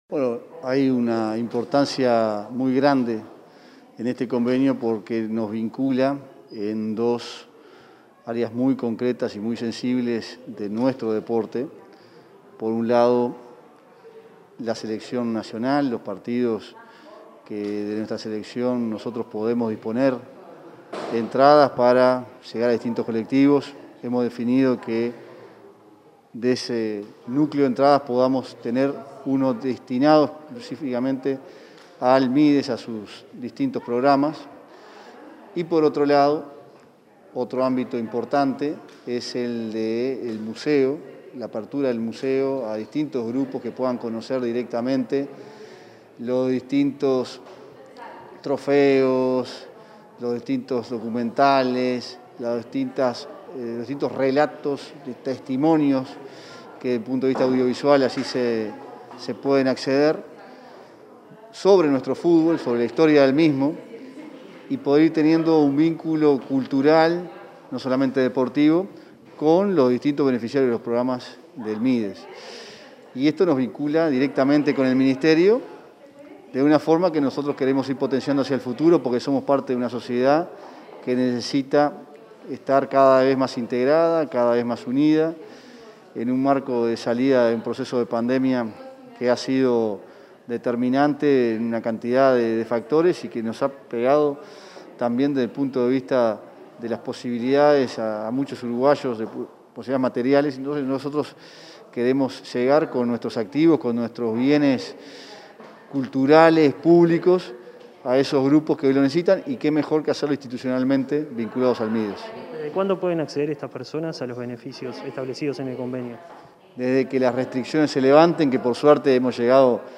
Entrevista al presidente de la AUF, Ignacio Alonso